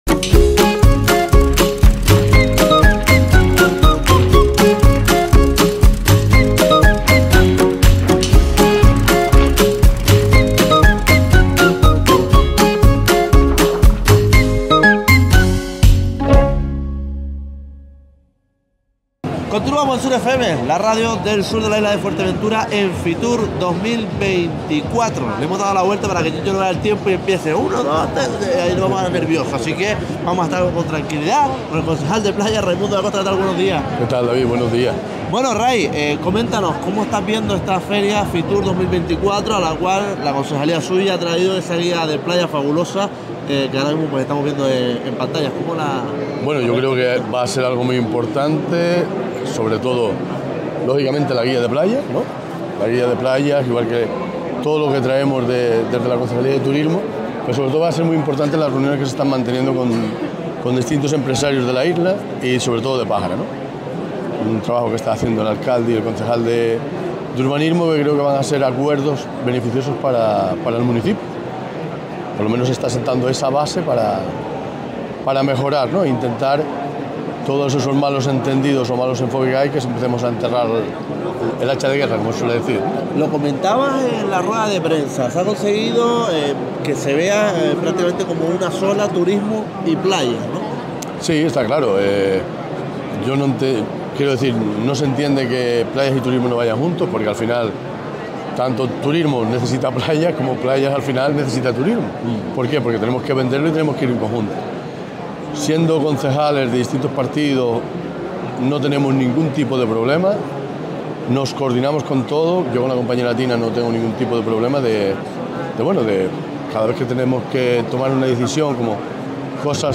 Fitur 2024: Entrevista a Raimundo Dacosta
Entrevistamos al concejal de Playas, Seguridad y Emergencia y Régimen Interior, Raimundo Dacosta en Fitur 2024.